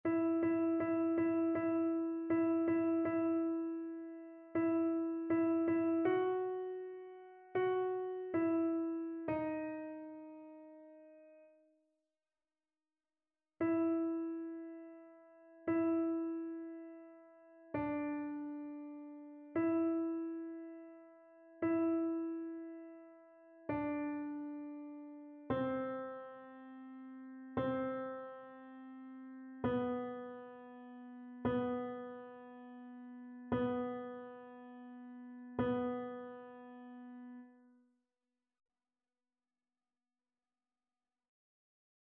Paroles : AELF - Musique : JFD
annee-a-temps-ordinaire-19e-dimanche-psaume-84-alto.mp3